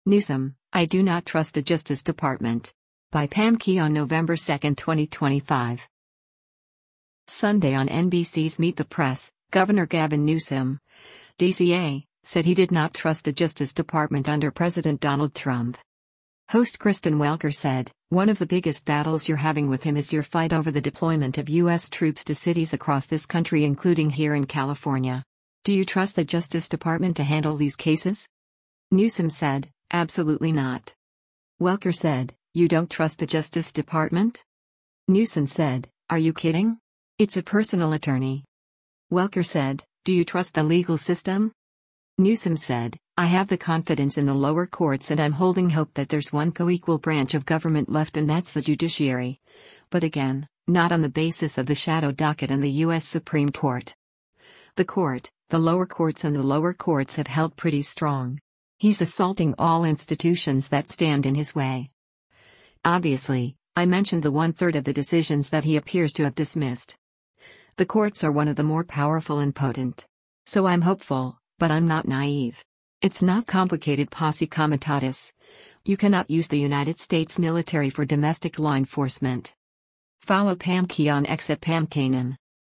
Sunday on NBC’s “Meet the Press,” Gov. Gavin Newsom (D-CA) said he did not trust the Justice Department under President Donald Trump.